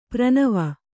♪ pranava